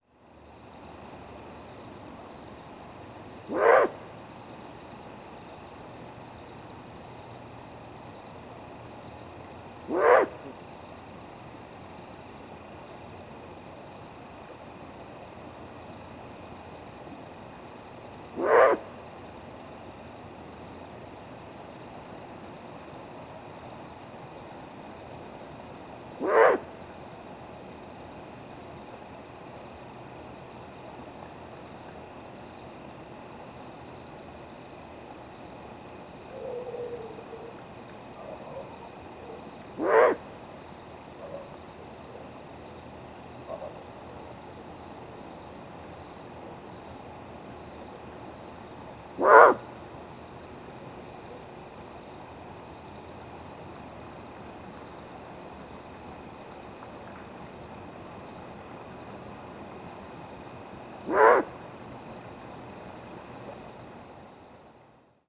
The variable begging call of juvenile Great Horned Owl can be similar but is usually more upward-arched or wavering, giving it a less monotone sound.
Great Horned Owl juvenile calling sequences:
Perched bird.